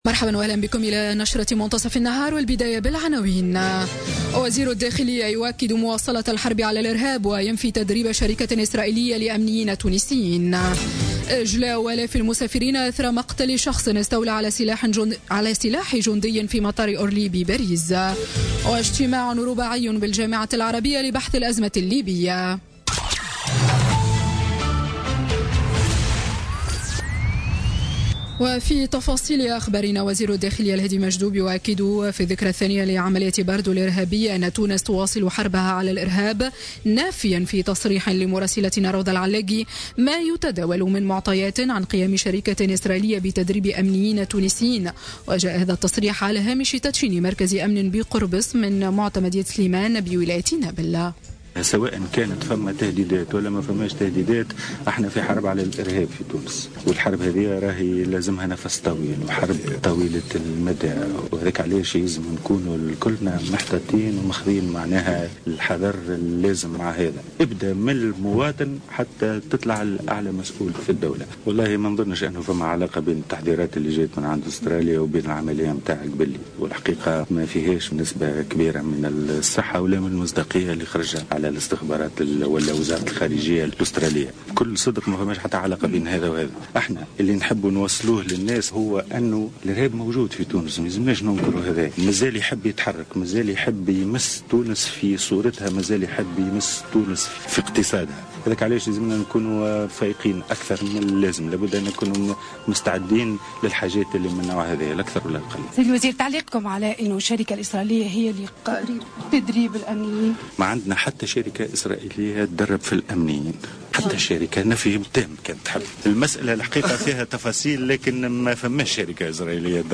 نشرة أخبار منتصف النهار ليوم السبت 18 مارس 2017